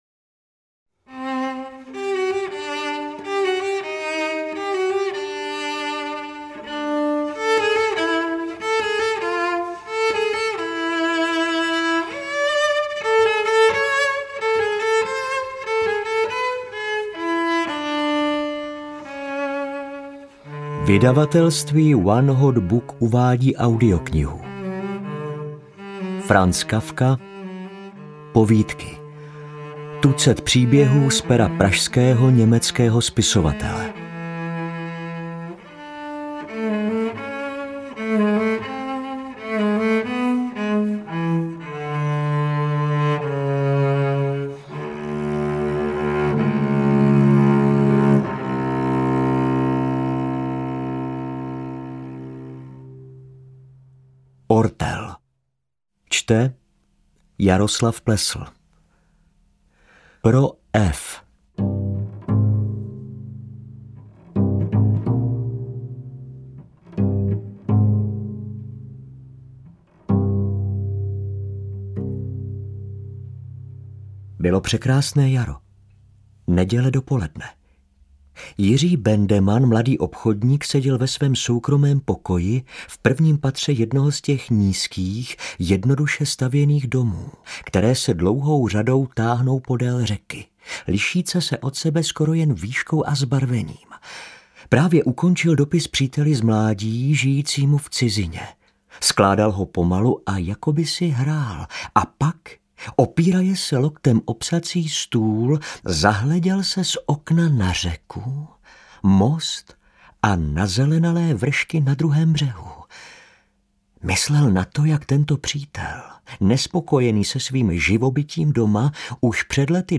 Interpreti:  Otakar Brousek ml., Lukáš Hlavica, Jan Holík, Václav Marhold, Jaromír Meduna, Miloslav Mejzlík, Václav Neužil, David Novotný, Jaroslav Plesl
Přední "neviditelní" herci vzdávají hold Franzi Kafkovi